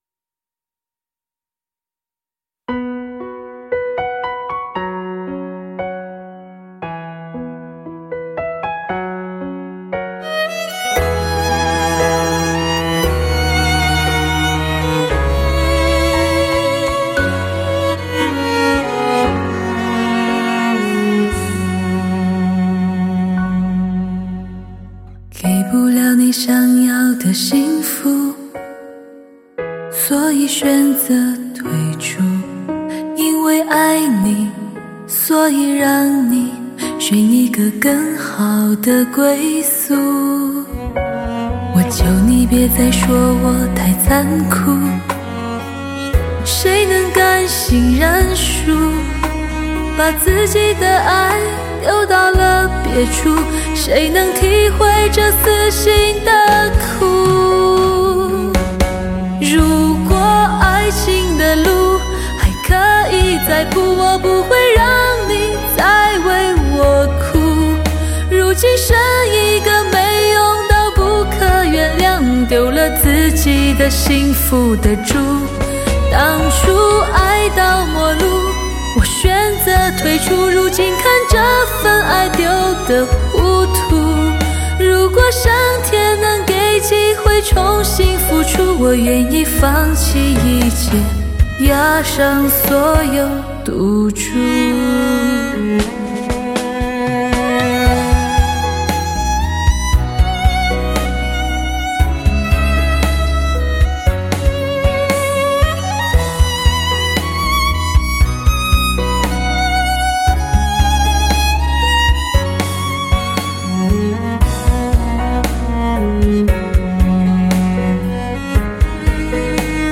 Surround7 1全方位，三维环绕HI-FI人声高解析，高临场天碟。